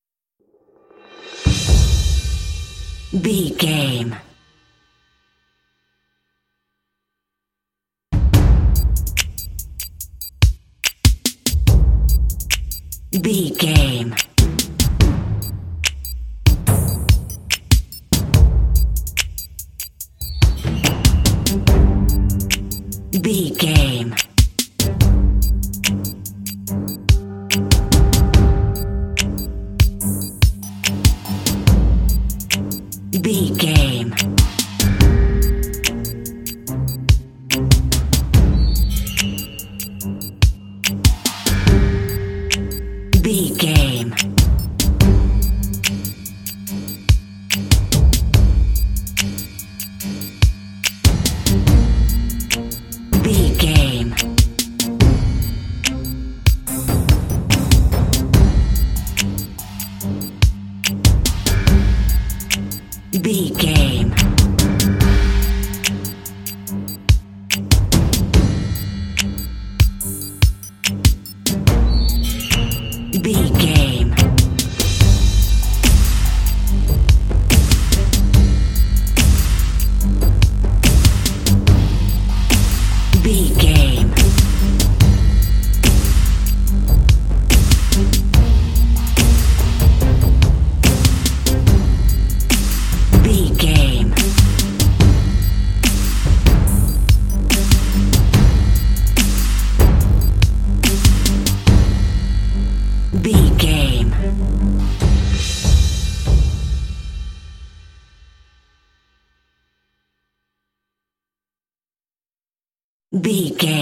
Aeolian/Minor
tension
scary
synthesiser
drums
strings
contemporary underscore